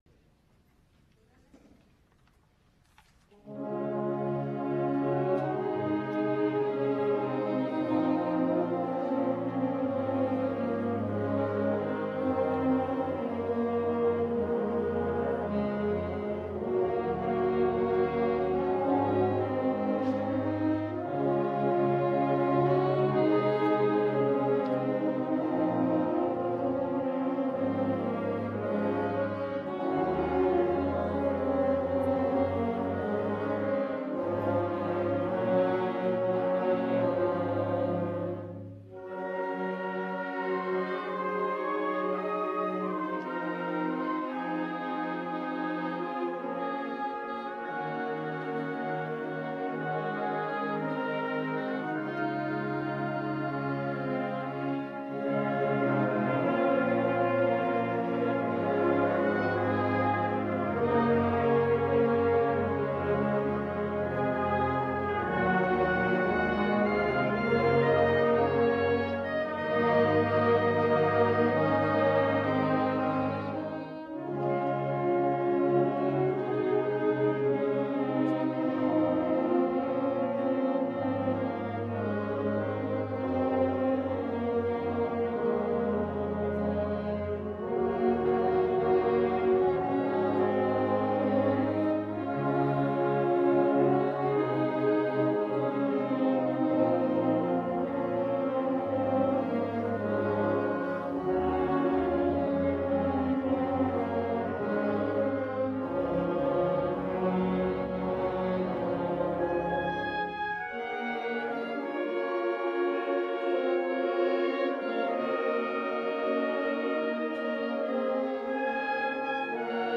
CONCERT BAND MUSIC With Quiet Courage Lord of the Dance National Emblem March SWING BAND MUSIC In the Mood Jumpin at the Woodside Little Darlin Sing Sing Sing Stardust OTHER EXAMPLES Here are recordings of the pieces that we played at our Mother’s Day concert on May 11, 2014.